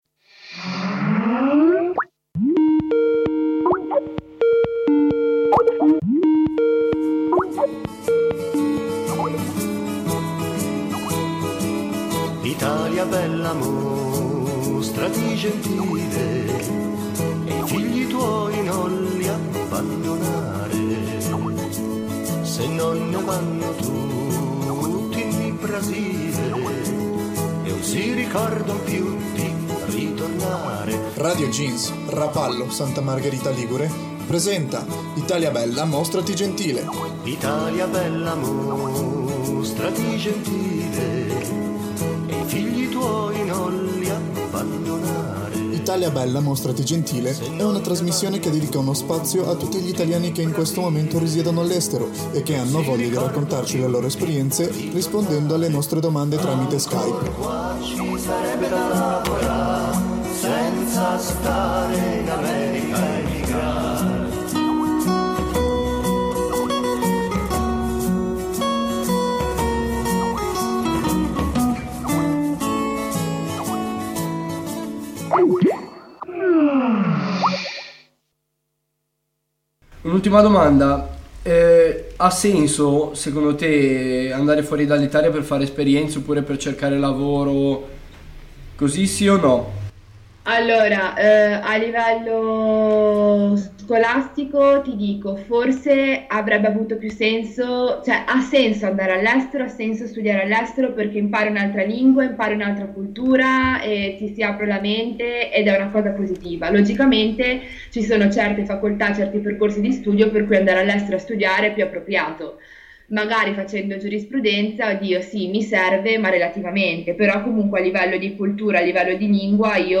Esce così il primo "best of" ovvero "il meglio di". Un riassunto di quanto emerso in questi mesi di viaggi via Skype; alla fine un insieme di voci e di storie simili e divertite, qualche volta preoccupate o disincantate rispetto alla situzione attuale del nostro paese e a quanto si possa trovare al di là dei confini geografici e mentali dell'Italia, croce e delizia per ognuno di noi.